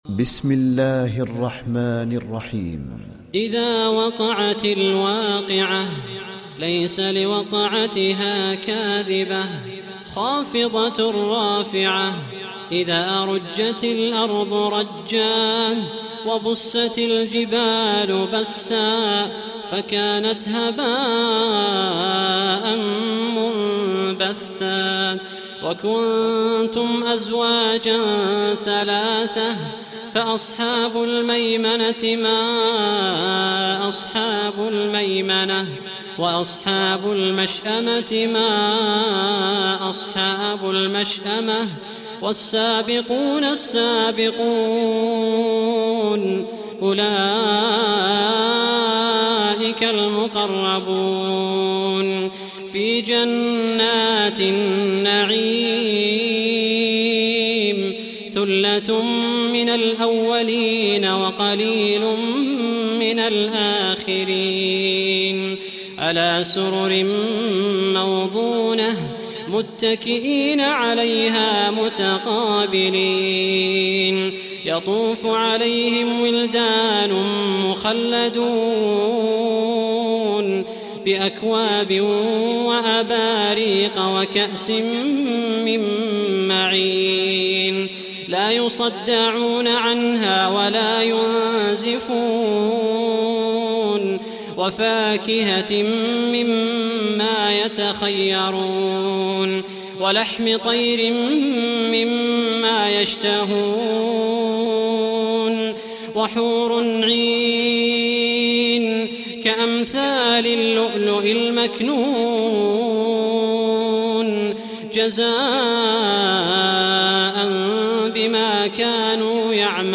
(رواية حفص)